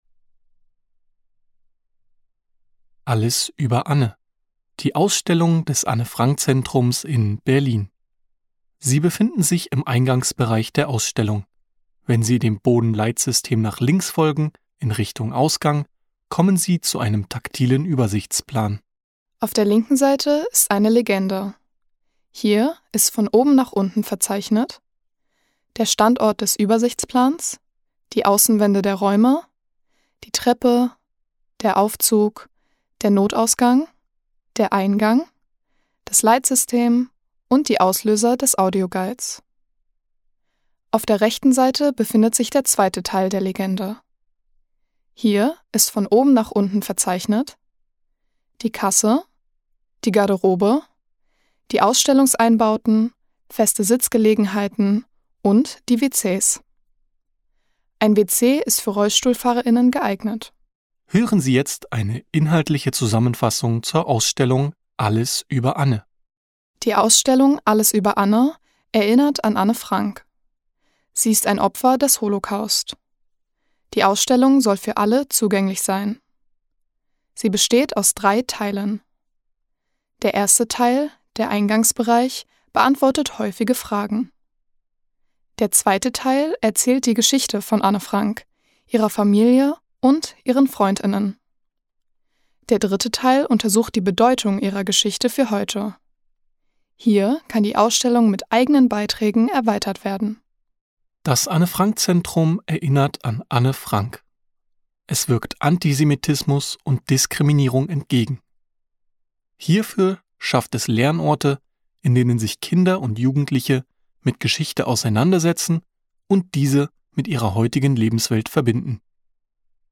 Wegbeschreibung mit Nutzung des Aufzugs vor Ort (Audio)Wegbeschreibung ohne Nutzung des Aufzugs vor Ort (Audio)Wegbeschreibung mit/ohne Nutzung des Aufzugs vor Ort (PDF)Audiodeskription der Ausstellung »Alles über Anne« (Audio)
Anne_Frank_Zentrum-Audio-Deskription-2024.mp3